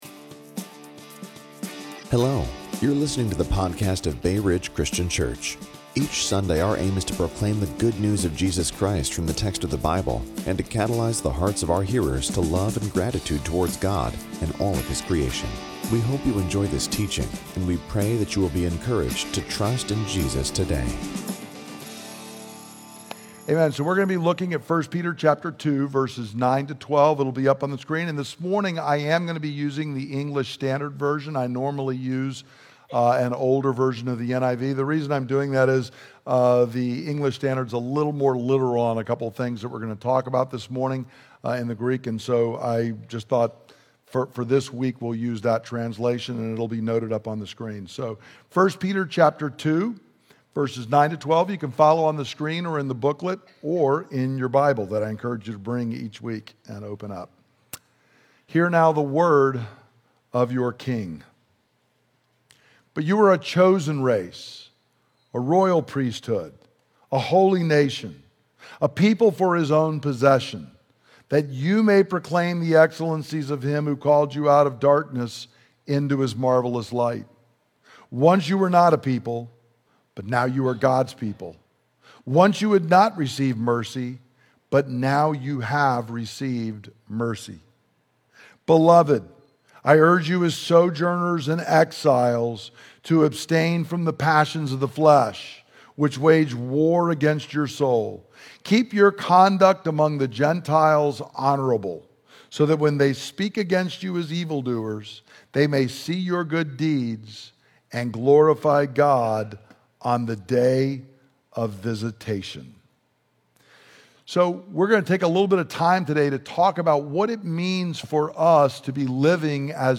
Listen to the teaching – Join us on Facebook or Youtube Live on Sunday @ 10:00 am